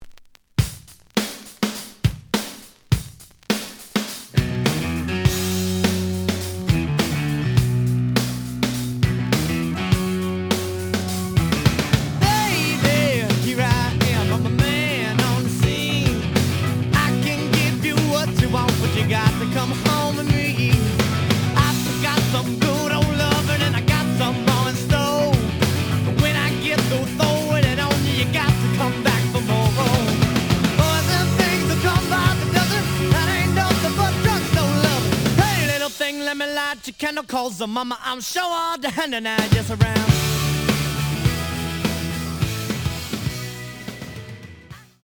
The audio sample is recorded from the actual item.
●Genre: Rock / Pop
Some damage on both side labels. A side plays good.)